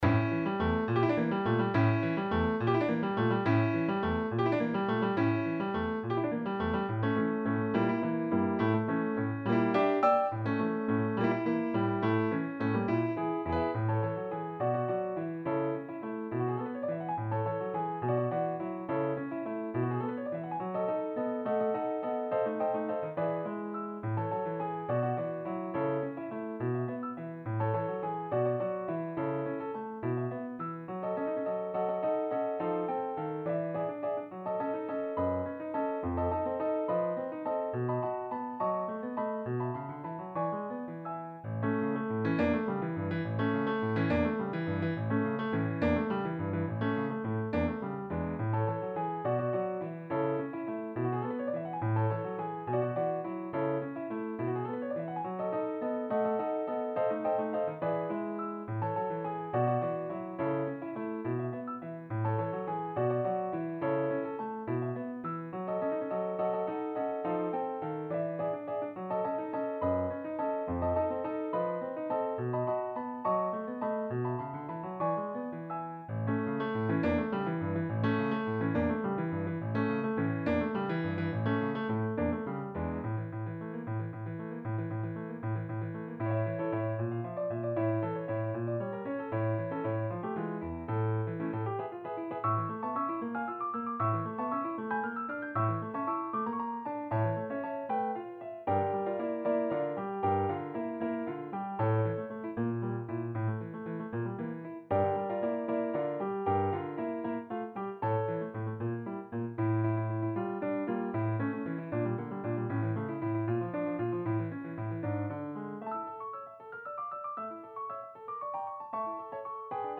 classical Spanish style.